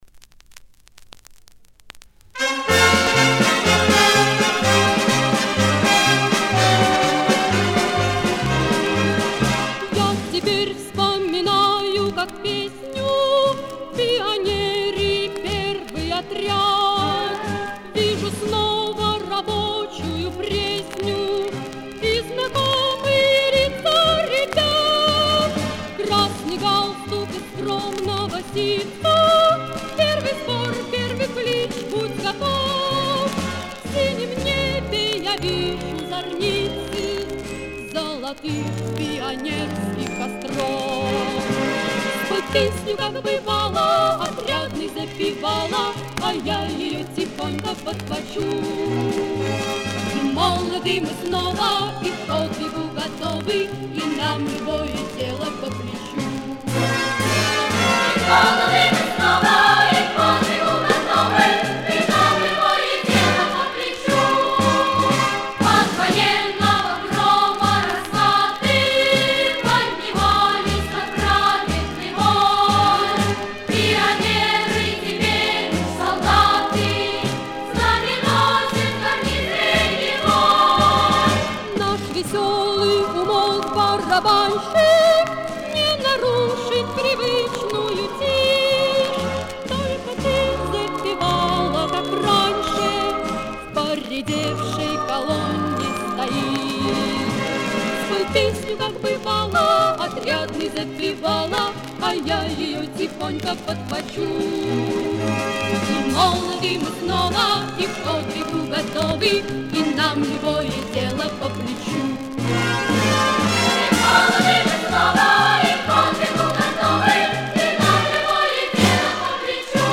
Пионерские песни